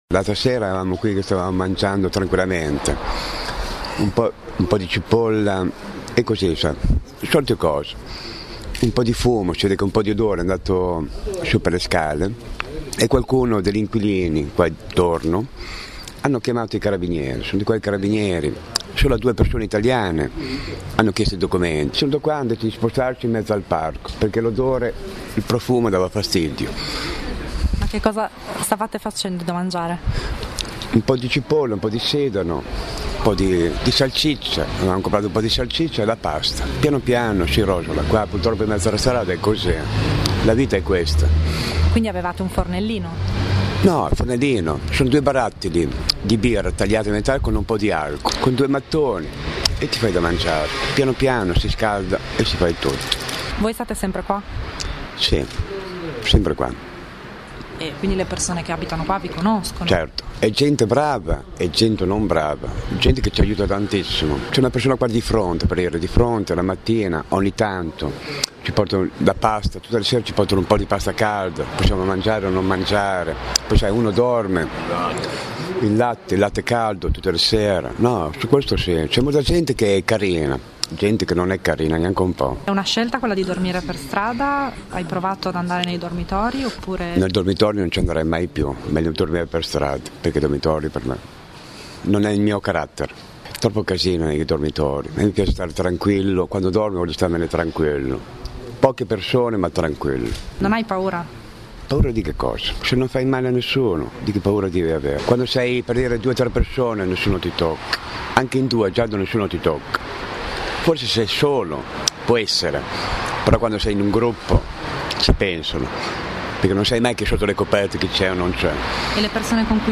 Reportage: vivere in strada
Abbiamo passato una serata con il servizio mobile di Piazza Grande, che ogni sera porta coperte e cibo a chi dorme in strada.